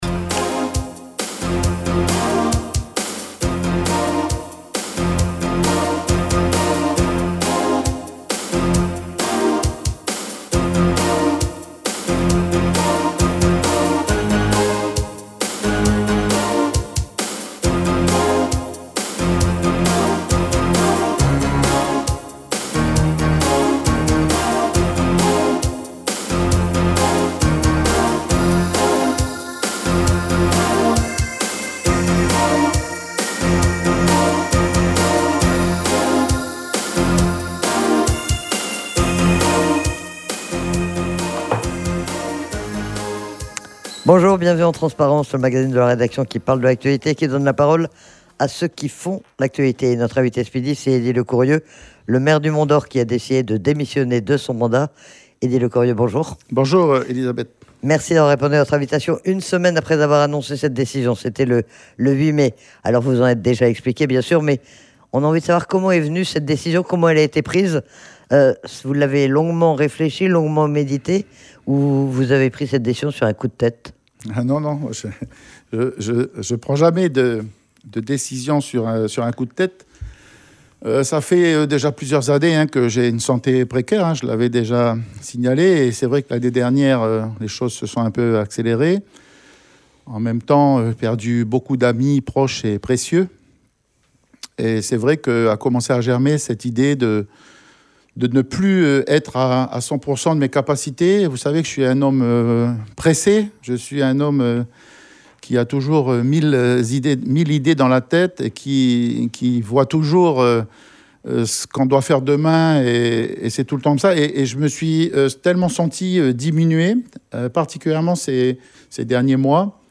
Eddie Lecourieux a annoncé, il y a une semaine, sa décision de démissionner de son mandat de maire du Mont Dore. Il était interrogé sur les raisons de ce choix mais aussi sur le bilan de son action et sur son analyse de la situation.